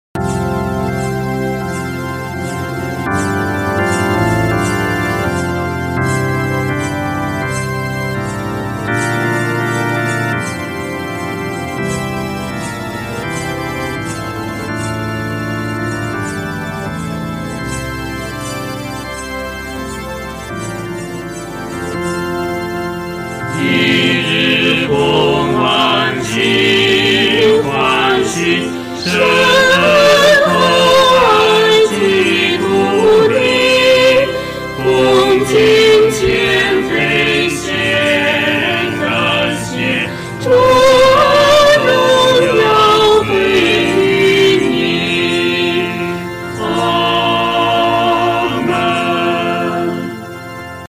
四声
它的进行简单朴素，所表达的是对神真诚的依赖与信靠。